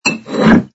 sfx_pick_up_bottle01.wav